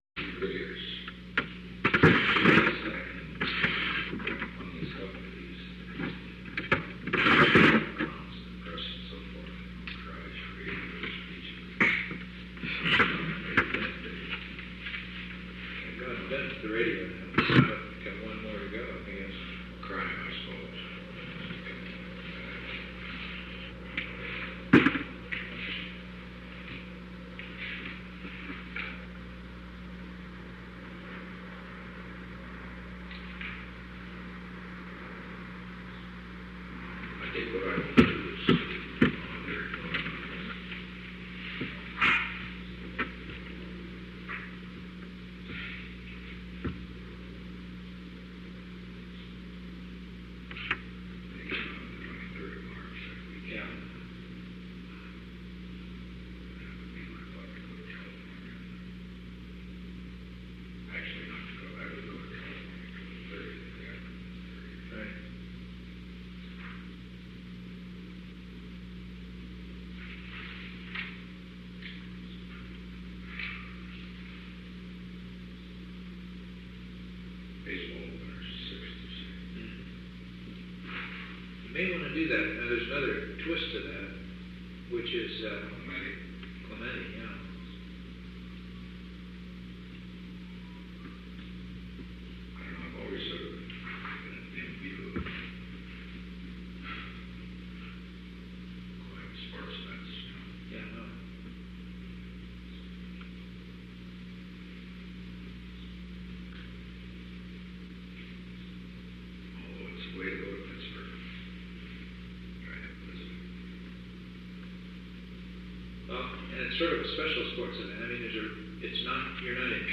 Conversation No. 868-18 Date: March 3, 1973 Time: 2:45 pm – 3:27 pm Location: Oval Office The President met with H. R. (“Bob”) Haldeman at 2:45 pm.